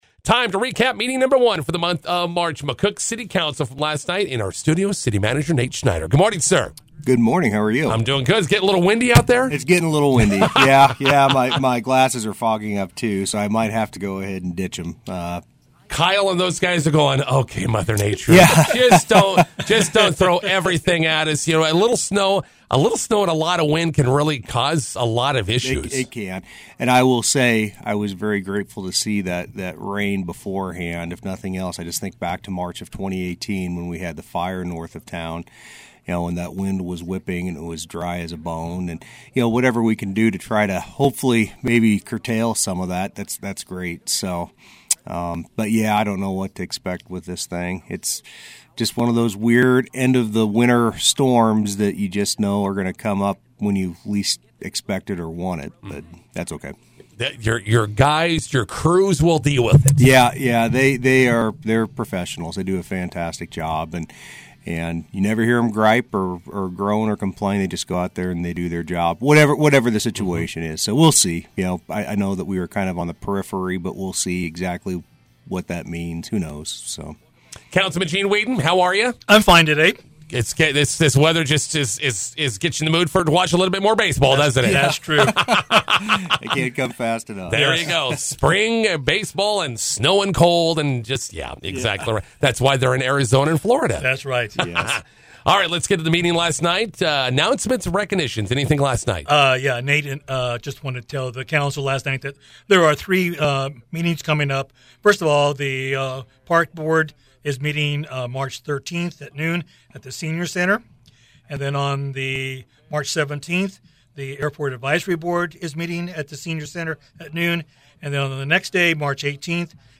INTERVIEW: McCook City Council meeting recap with City Manager Nate Schneider and Councilman Gene Weedin.